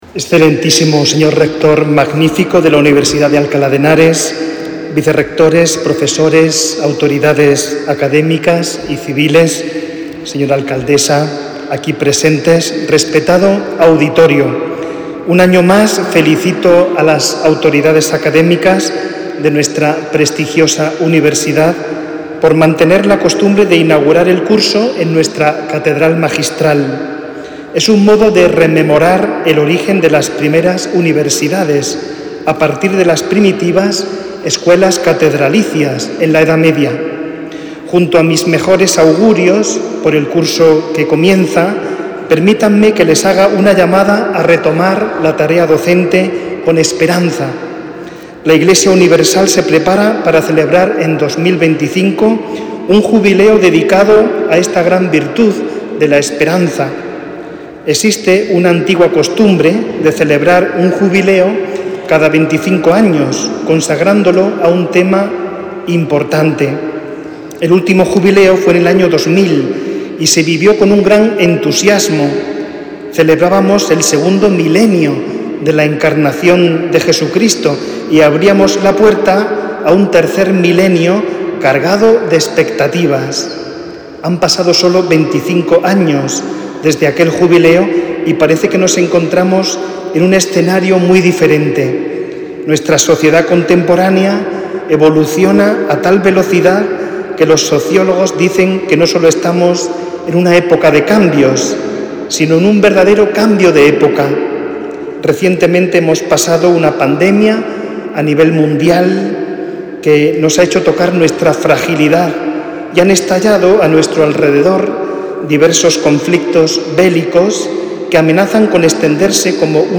Los actos dieron comienzo en la Catedral-Magistral de Alcalá, donde el obispo de Alcalá, Mons. Antonio Prieto Lucena, dirigió unas palabras de saludo a los presentes.
Palabras de saludo del obispo de Alcalá
saludo-obispo-alcala-apertura-curso-UAH-24-25.mp3